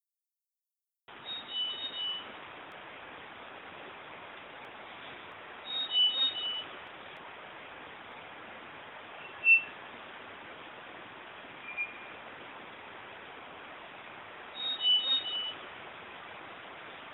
Myadestes melanops Black-faced Solitaire Solitario Carinegro (Jilguero)
solitaireVoz0019.wav